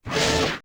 fs_mechanical_lg.wav